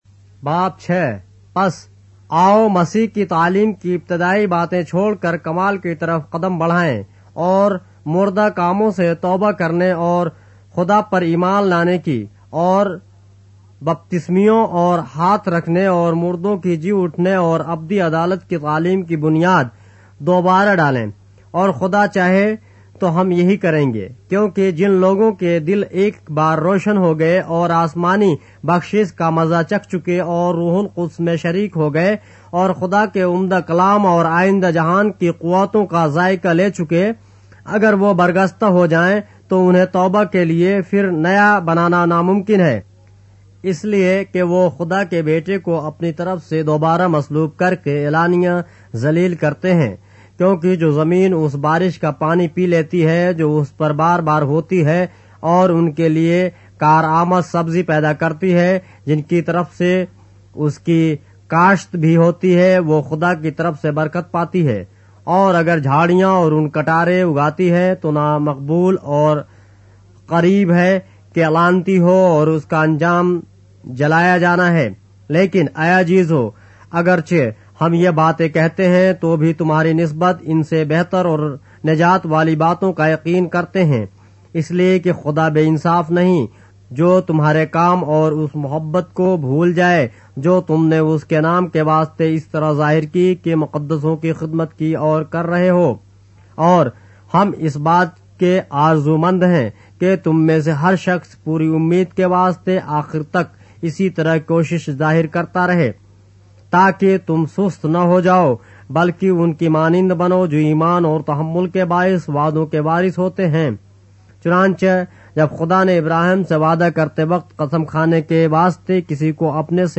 اردو بائبل کے باب - آڈیو روایت کے ساتھ - Hebrews, chapter 6 of the Holy Bible in Urdu